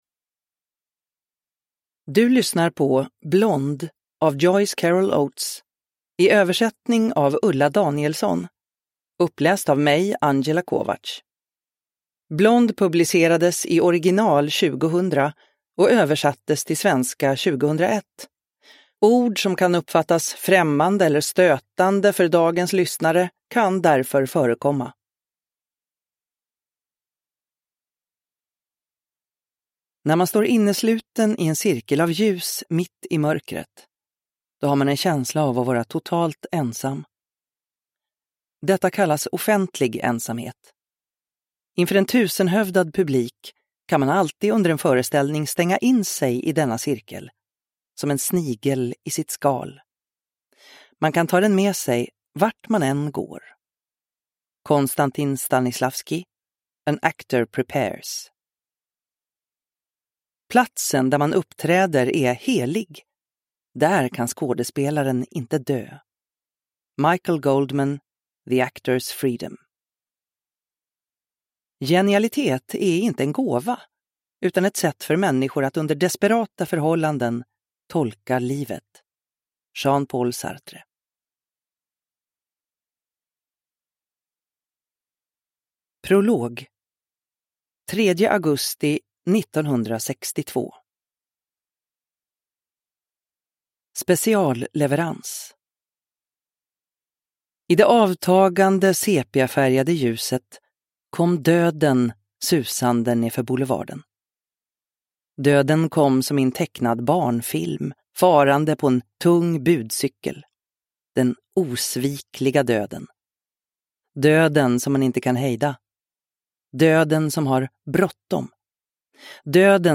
Blonde – Ljudbok – Laddas ner